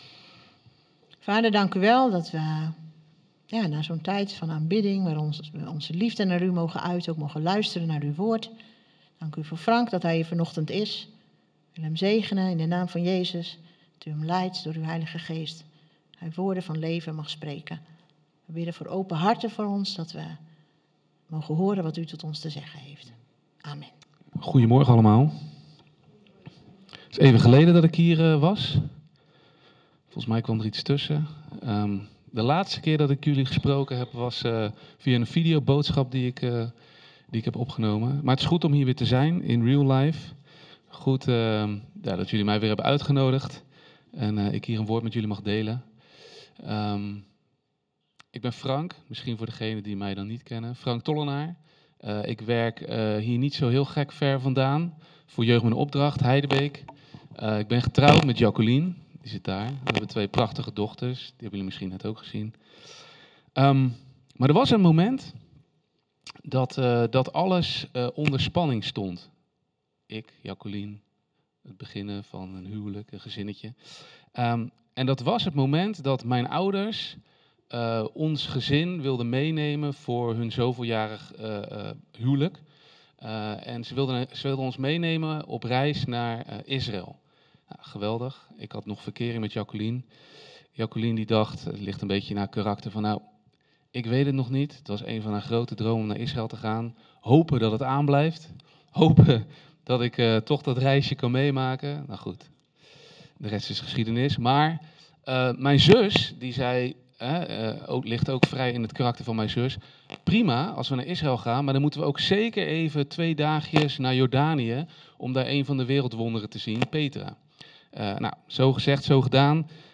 Preken
Evangelische christengemeente in Heerde